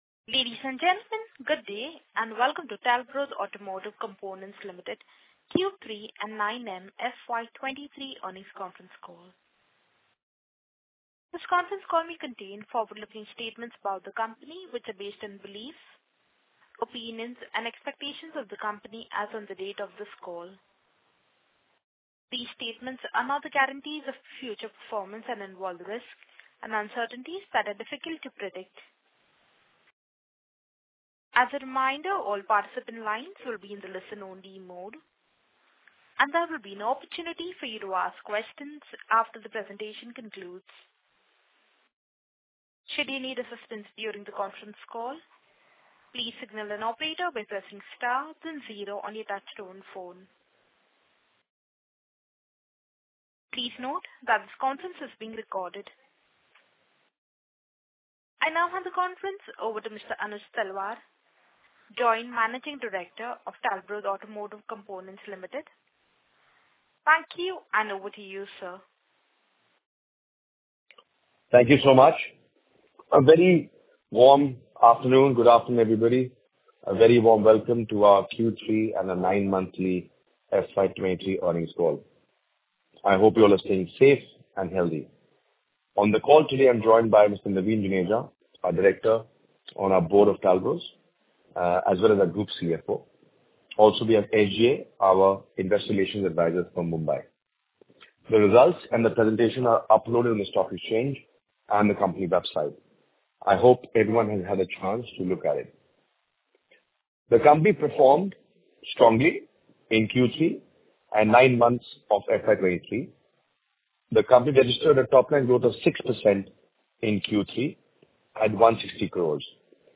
AUDIO/VIDEO RECORDING OF EARNINGS CALL
Q3-9M-FY23-Earning-Call-Audio-Recording.mp3